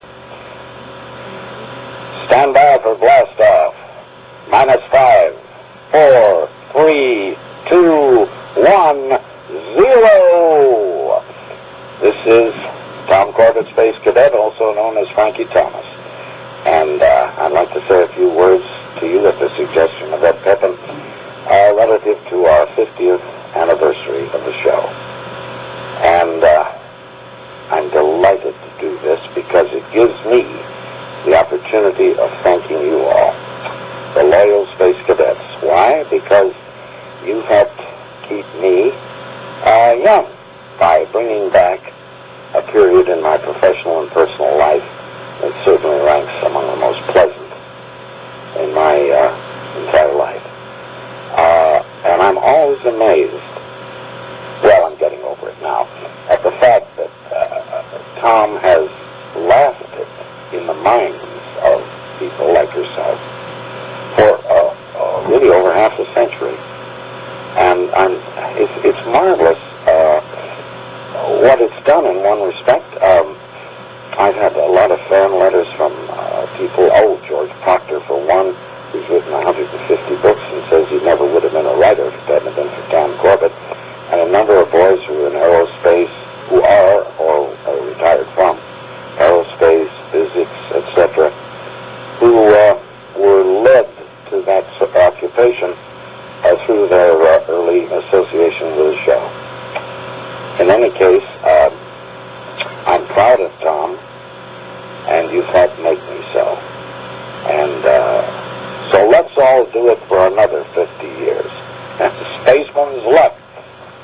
The following sound clips are the longer greetings from our favorite Polaris crew members.